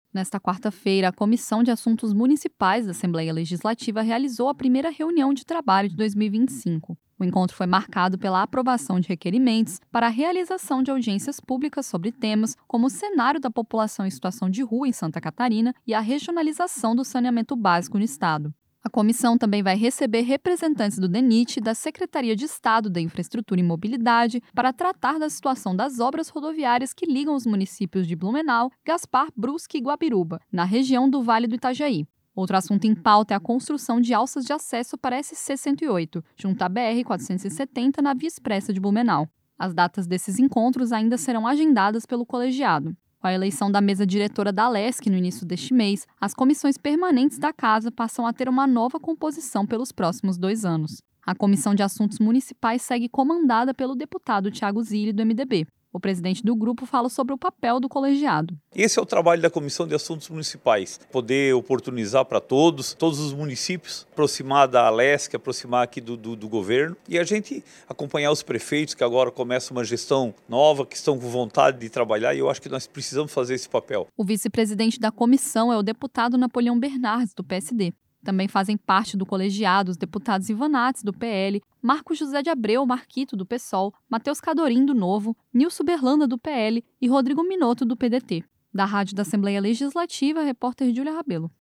Entrevista com:
- deputado Tiago Zilli (MDB), presidente da Comissão de Assuntos Municipais.